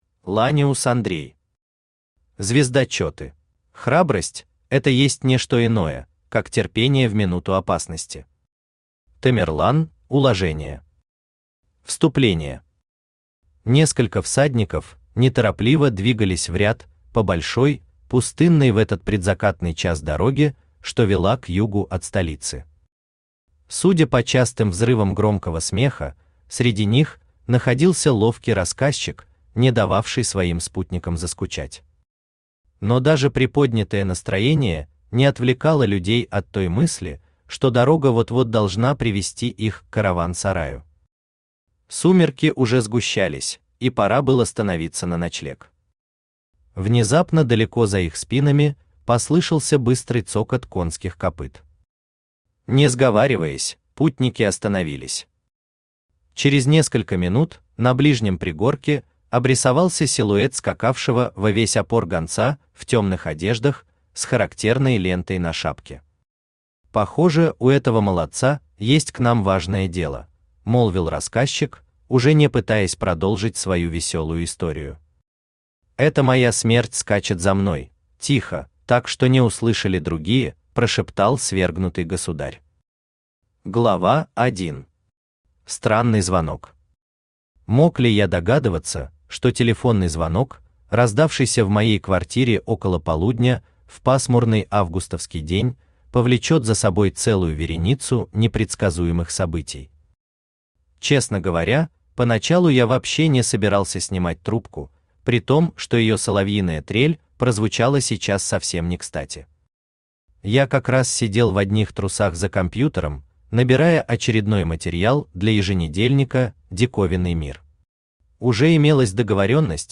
Aудиокнига Звездочеты Автор Ланиус Андрей Читает аудиокнигу Авточтец ЛитРес. Прослушать и бесплатно скачать фрагмент аудиокниги